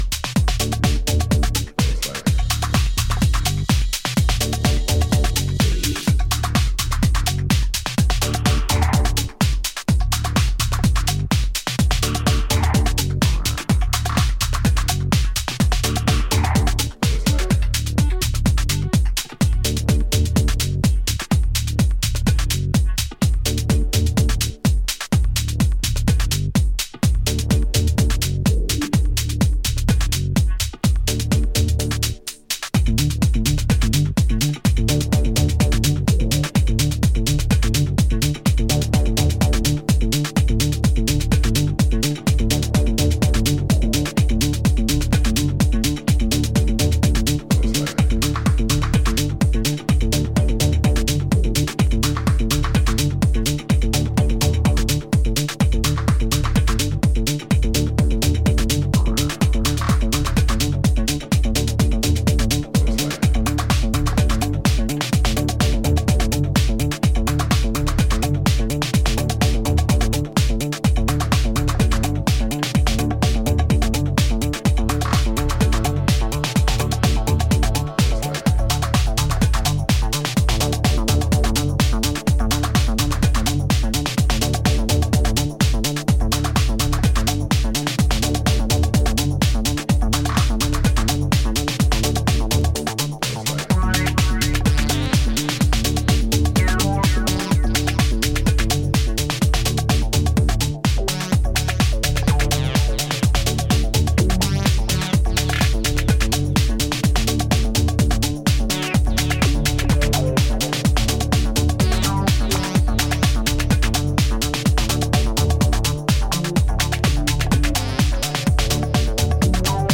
120台の落ち着いたテンポで展開するソリッドなグルーヴはダンサーの気分を高揚させるフロアムードの下地作りにバッチリ。